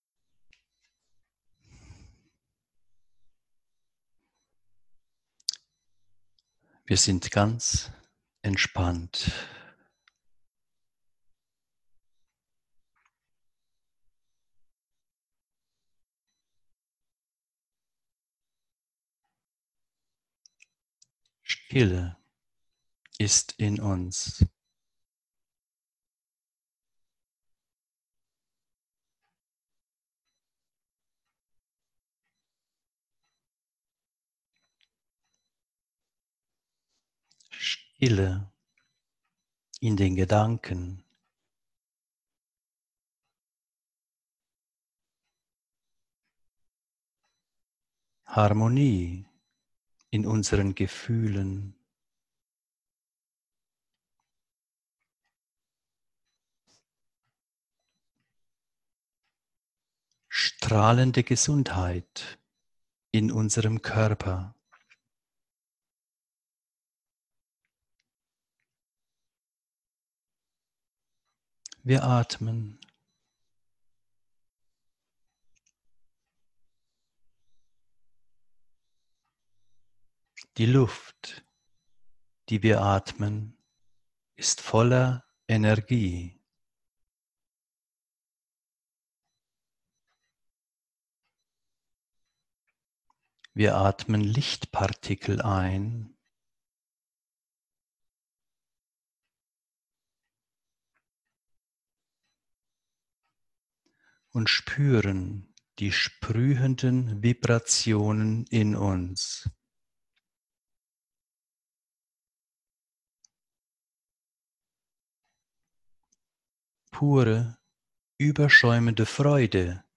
Meditation Meditation zur Entfaltung von Friede und Freiheit im Herzen Diese geführte Meditation hilft bei der Entfaltung von Friede und Freiheit im Herzen.